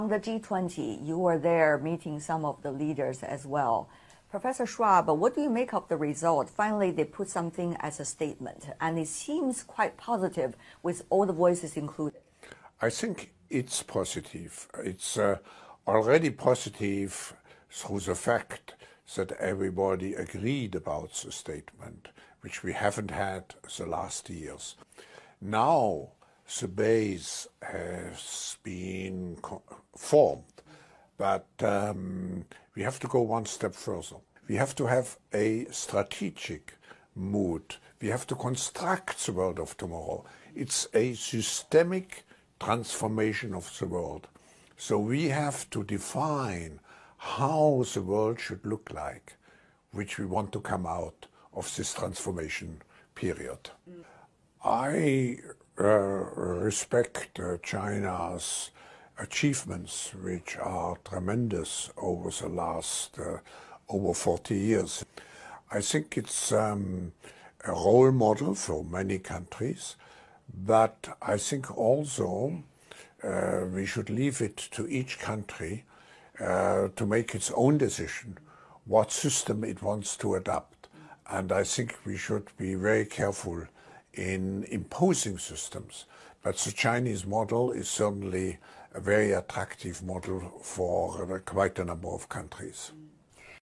klaus schwab cgtv interview china is a role model for many countr